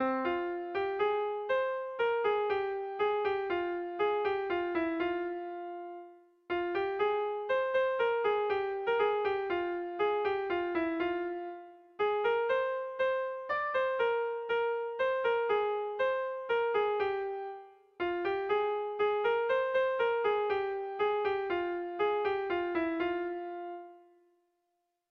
Sentimenduzkoa
Doinu oso ederra.
Zortziko ertaina (hg) / Lau puntuko ertaina (ip)
A1A2BA2